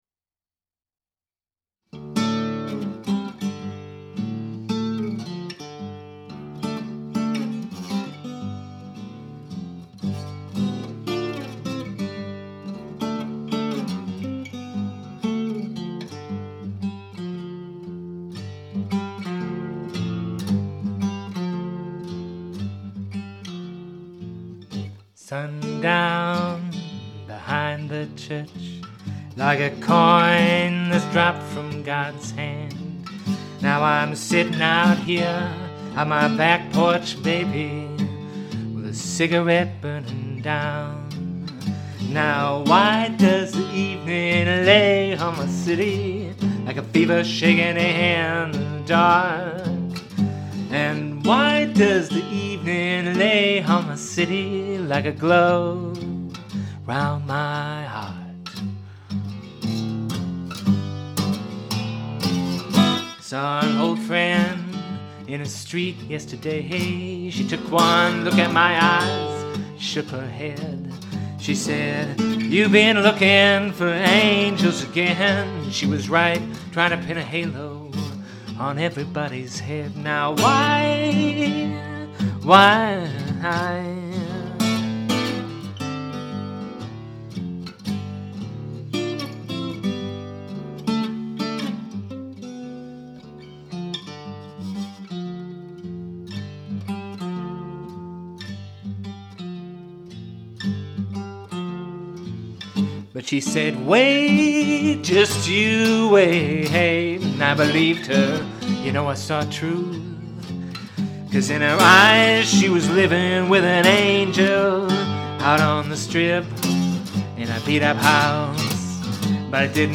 guitar
His instrumental carried a compelling walking base, and a melodic stairway of chords that began at ground level and then went subterranean. They started back up just as you were buried up to your neck: a little grit, a lot of muscle.